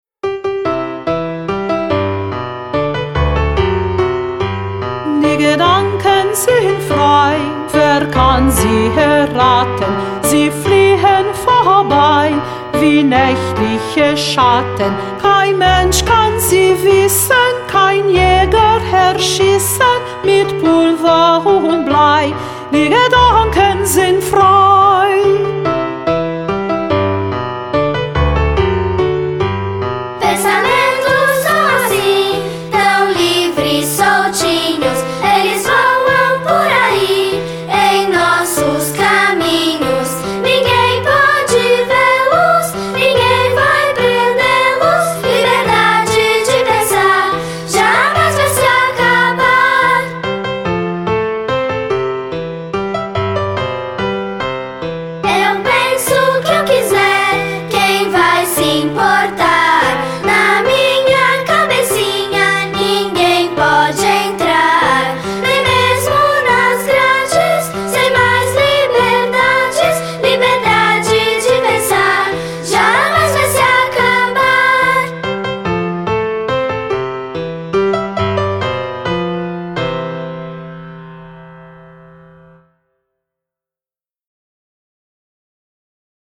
(Canção popular alemã.
folclore alemão